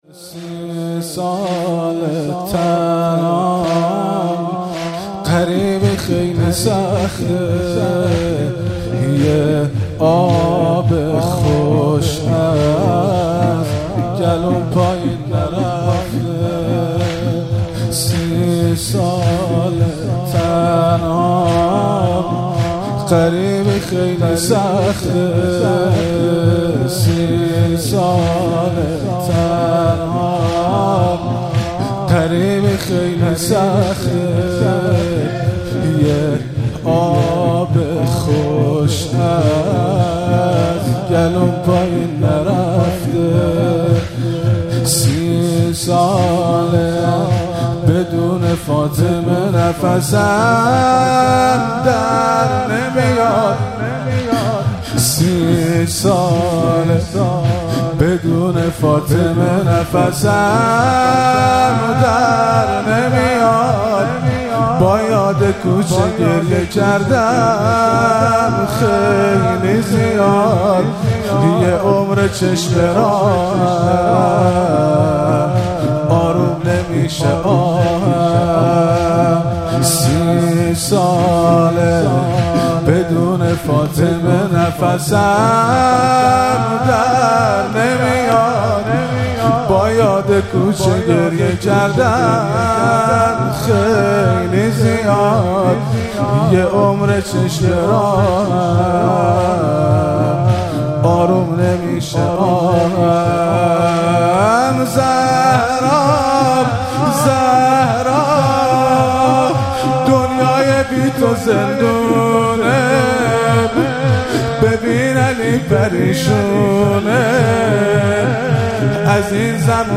مراسم مناجات خوانی و احیای شب بیست و یکم و عزاداری شهادت حضرت امیرالمومنین علی علیه السلام ماه رمضان 1444
زمینه- سی ساله تنهام، غریبی خیلی سخته